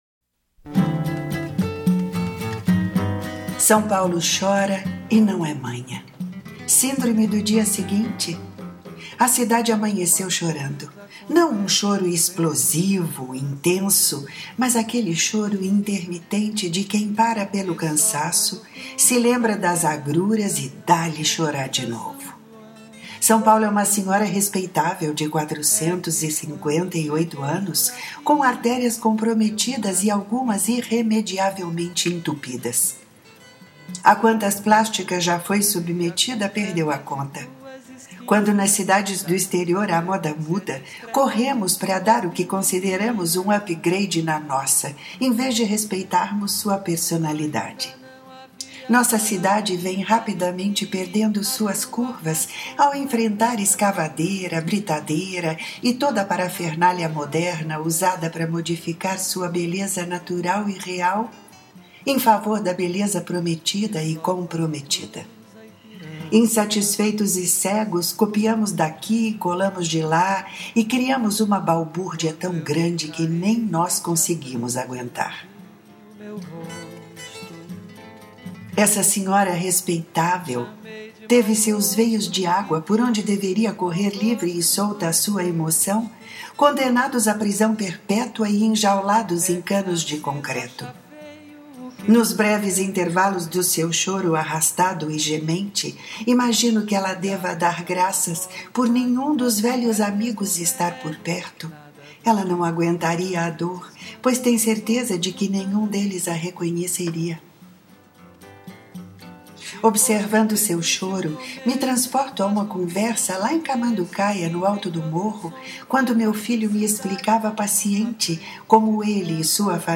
Ouça este texto na voz e sonorizado pela autora